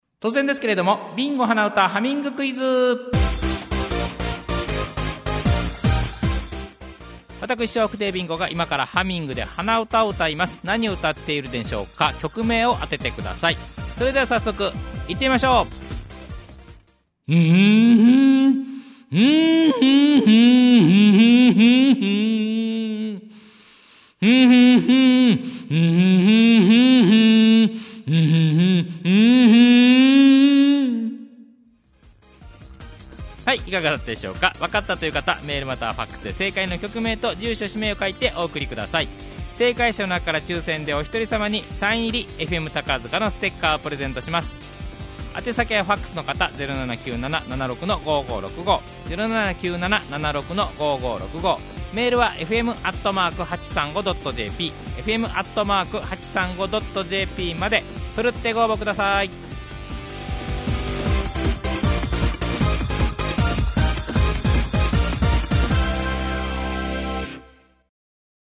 第１ヒントです。